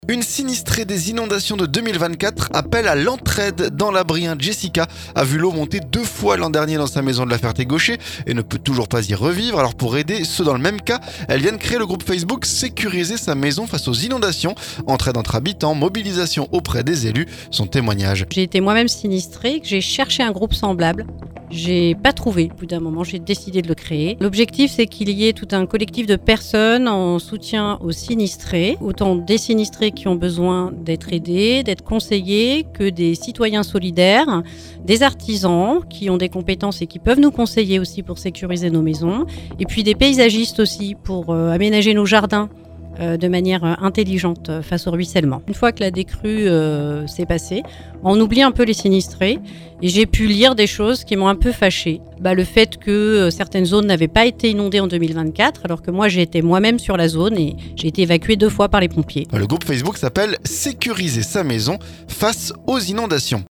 Entraide entre habitants, et mobilisation auprès des élus. Son témoignage.